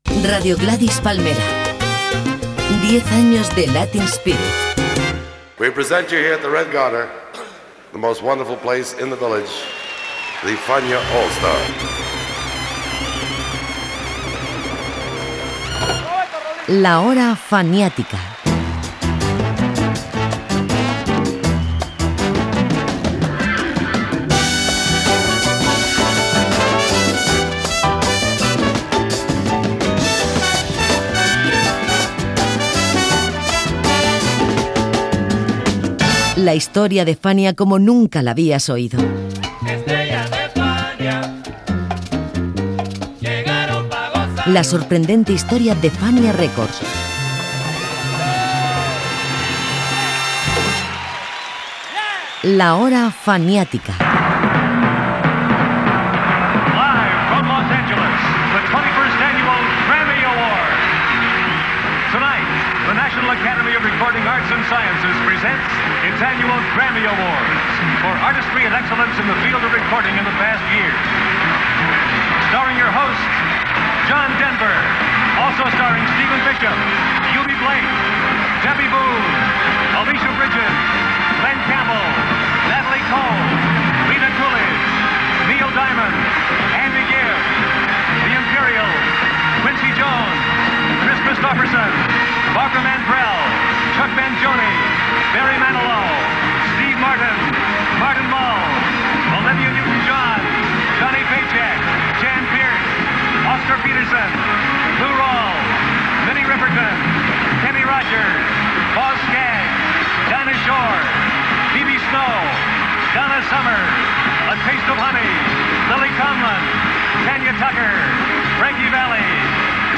Indicatiu dels deu anys de l'emissora, careta del programa, inici del programa dedicat a Tito Puente quan va guanyar el seu primer premi Grammy
Musical
FM